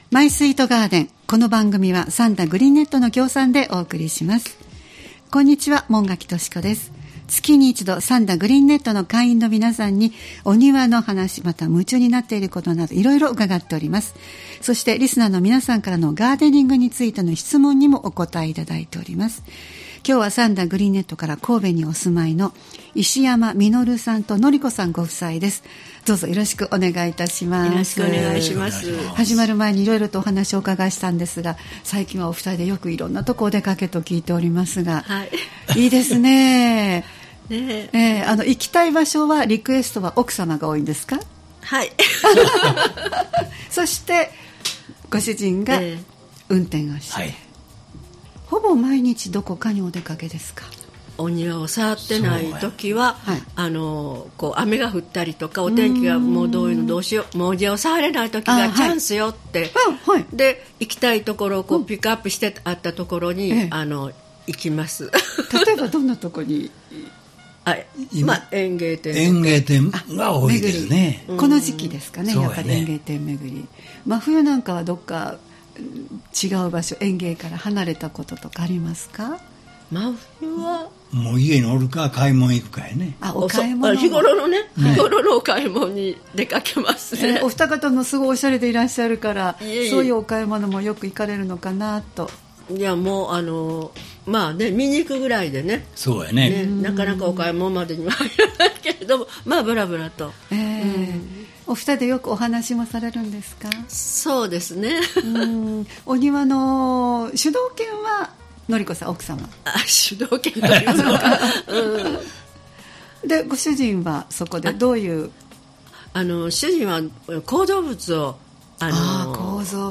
毎月第2火曜日は兵庫県三田市、神戸市北区、西宮市北部でオープンガーデンを開催されている三田グリーンネットの会員の方をスタジオにお迎えしてお庭の様子をお聞きする「マイスイートガーデン」（協賛：三田グリーンネット）をポッドキャスト配信しています（再生ボタン▶を押すと番組が始まります）